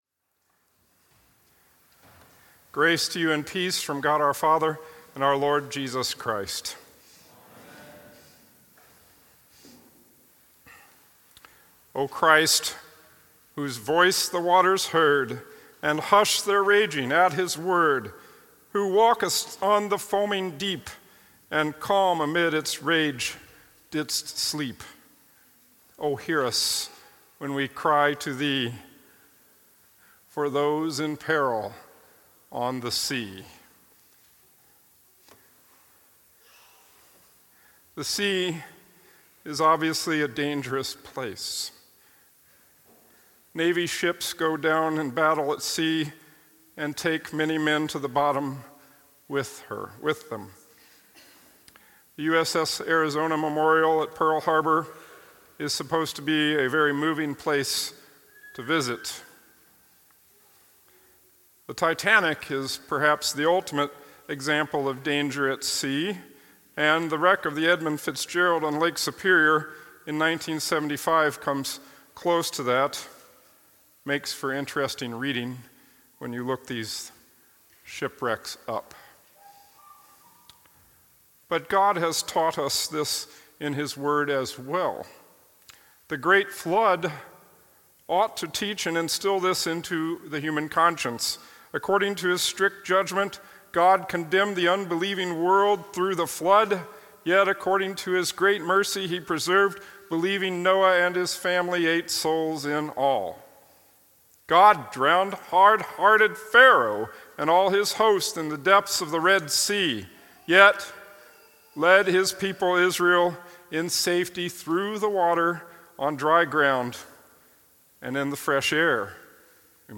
Epiphany Podcast